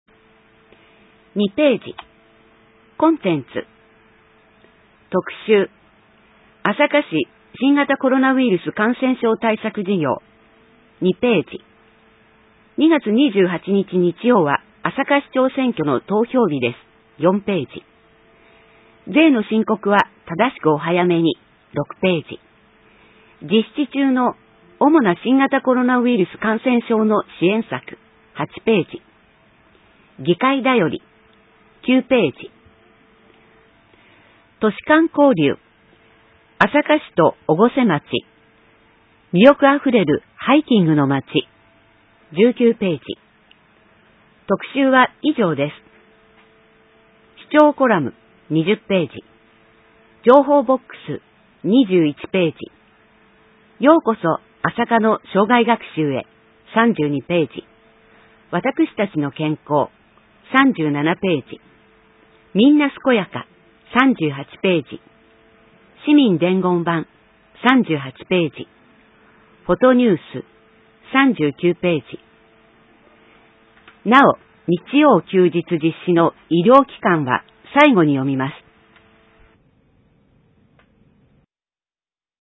｢声の広報あさか」は、市内のボランティア「朝霞リーディングサークル」のご協力で、視覚に障害がある方のご自宅にＣＤ（デイジー形式）を郵送しています。